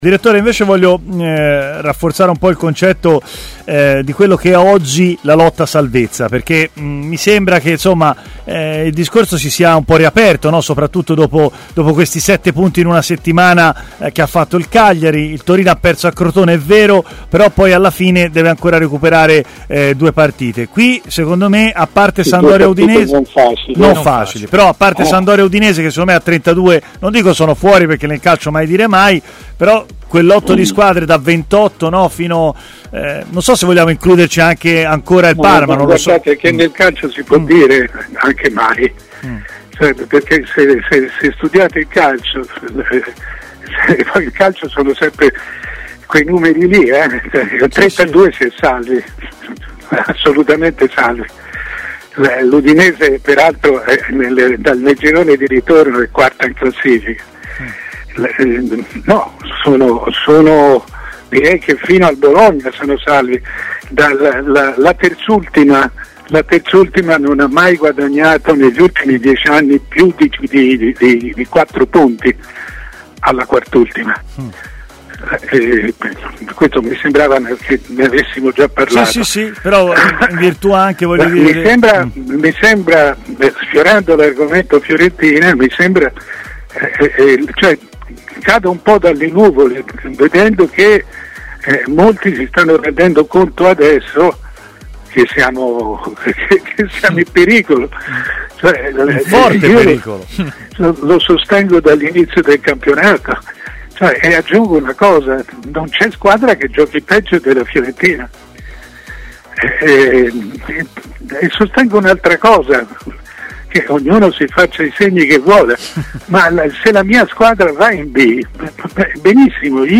Mario Sconcerti, nota firma del giornalismo italiano è intervenuto in diretta su TMW Radio, nel corso della trasmissione Stadio Aperto.